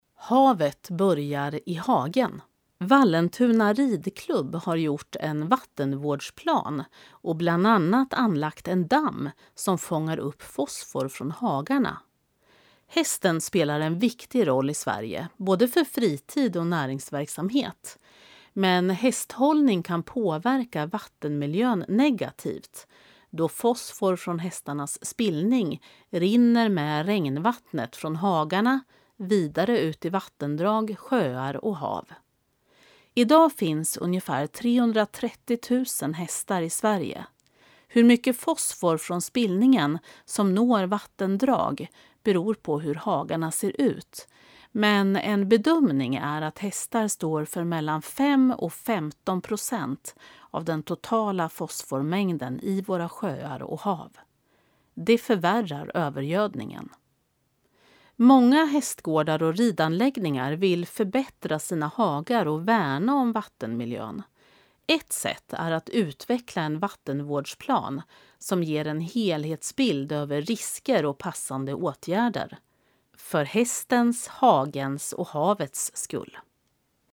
Klicka här för att lyssna till texten, inläst av professionell uppläsare